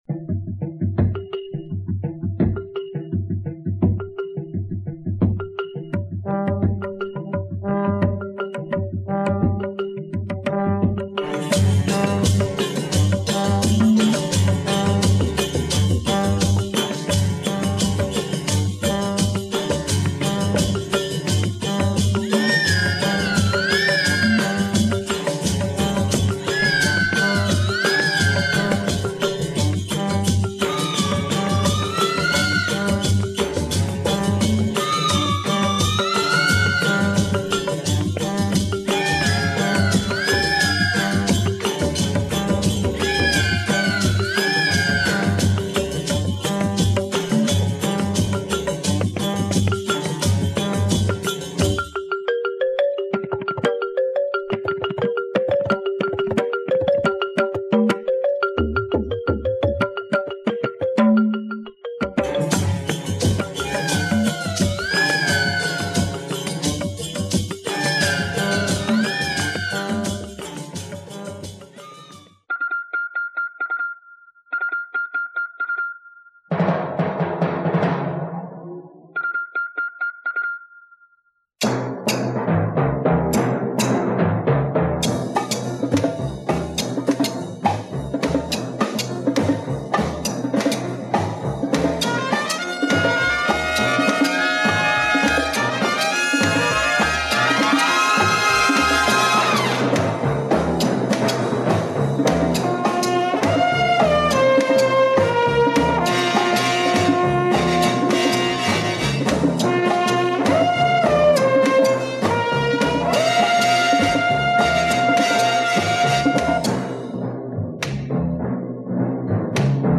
Beautiful French jazz session.